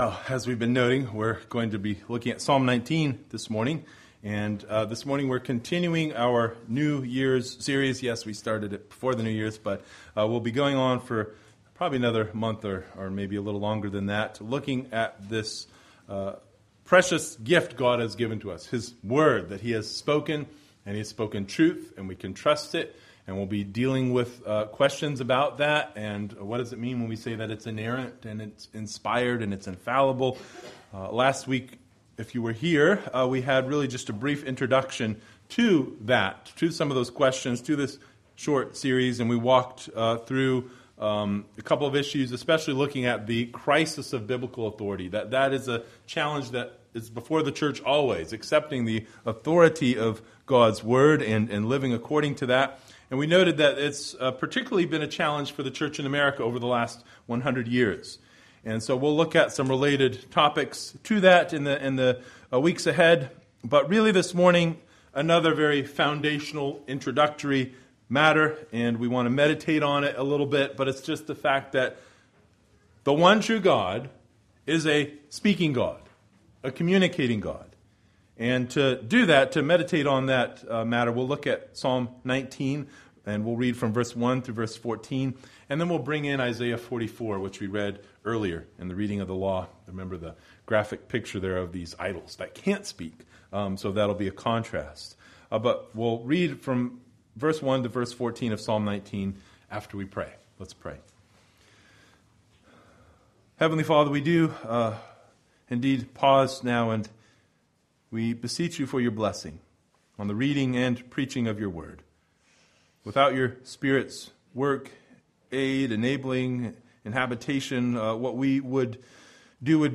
Passage: Psalm 19 Service Type: Sunday Morning